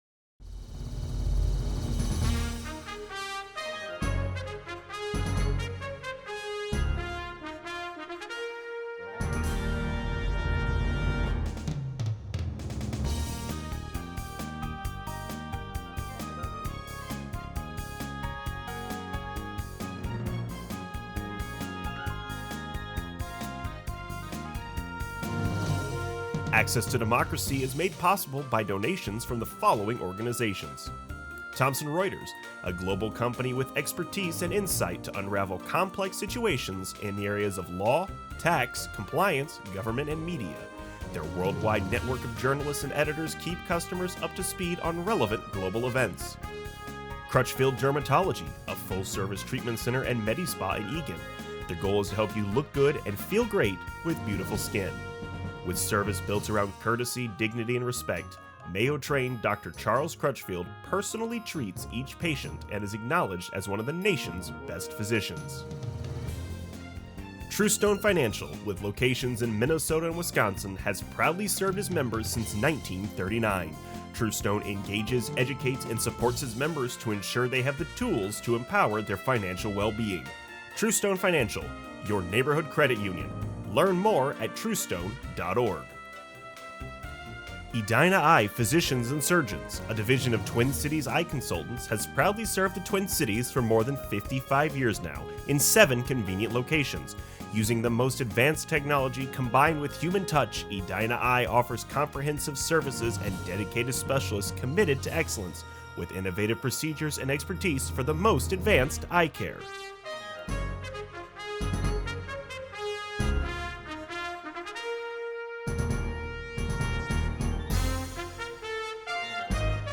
a discussion about the potential Supreme Court decision overturning abortion rights in Roe v Wade, the ongoing assault on our democracy over voting rights and redistricting, issues affecting farm families in rural Minnesota, and how to revitalize our rural economies and fight climate change.